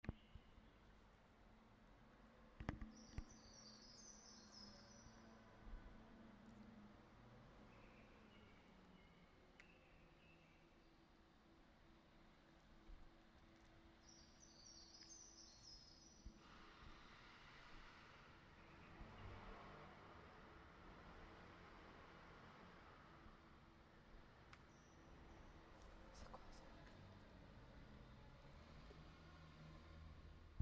rue autour des Halles
traffic vopiture, vent